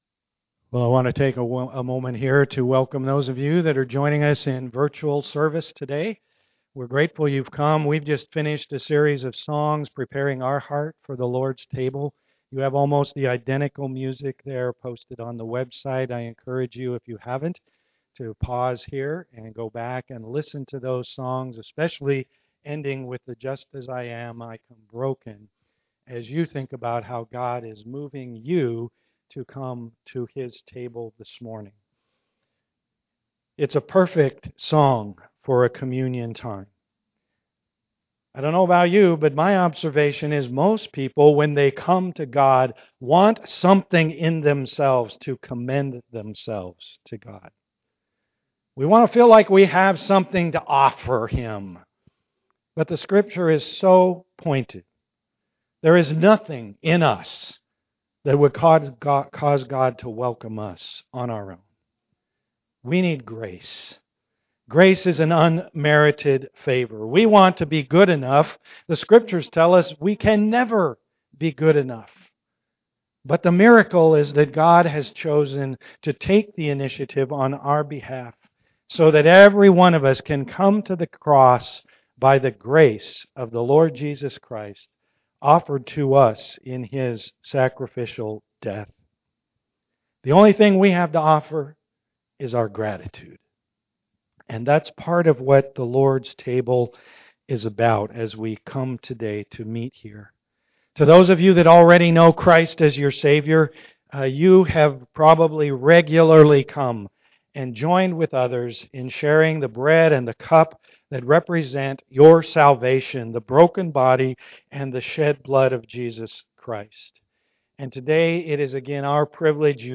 Psalm 16 Service Type: am worship Communion will open our video service this week.